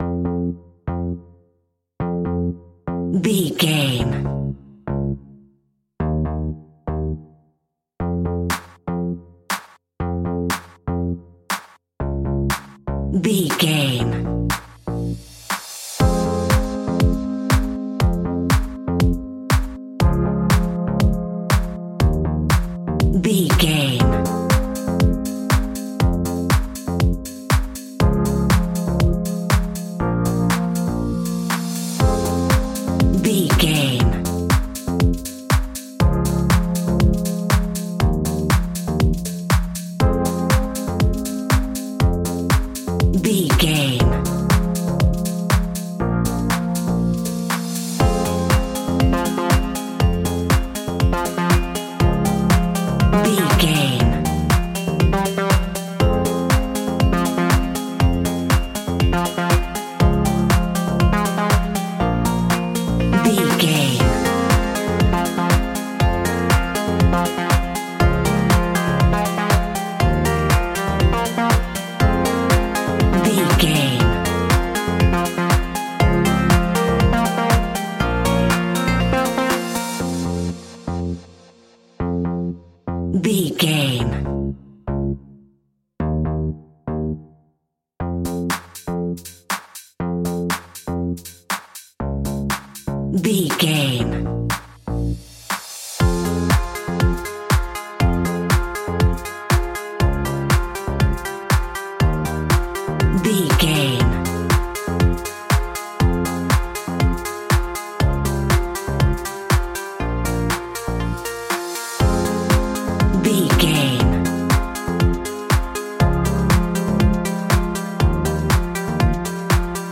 Aeolian/Minor
groovy
uplifting
driving
energetic
drum machine
synthesiser
funky house
electro
nu disco
upbeat
funky guitar
synth bass